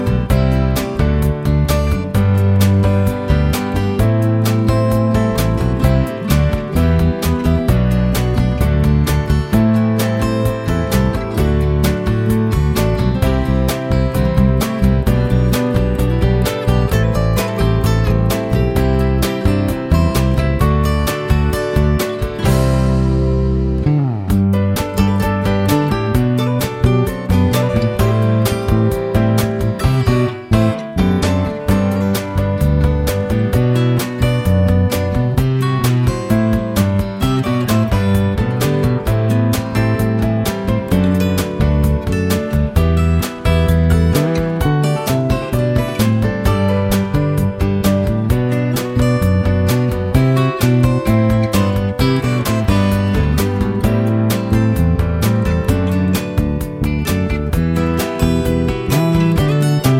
no Backing Vocals Country (Male) 3:48 Buy £1.50